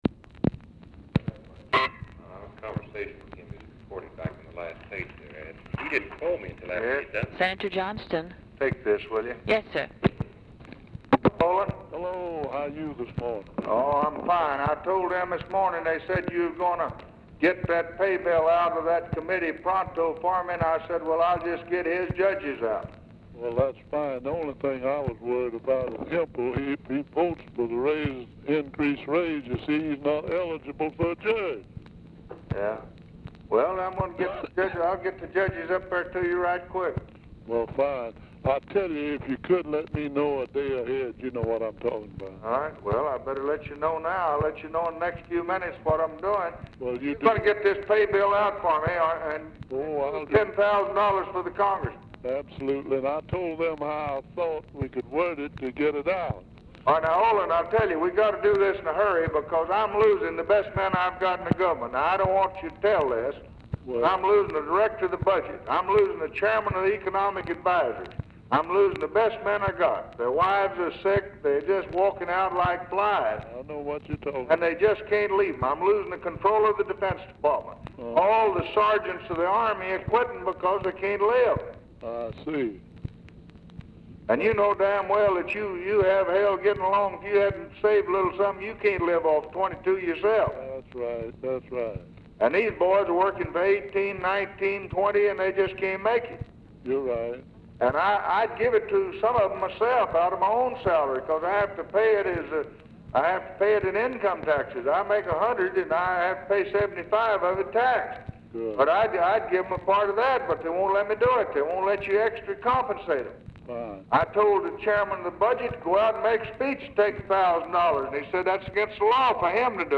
Telephone conversation # 3029, sound recording, LBJ and OLIN JOHNSTON, 4/14/1964, 1:40PM | Discover LBJ
LBJ IS ON SPEAKERPHONE; BILL MOYERS? SPEAKS BRIEFLY TO LBJ PRECEDING THE CONVERSATION
Format Dictation belt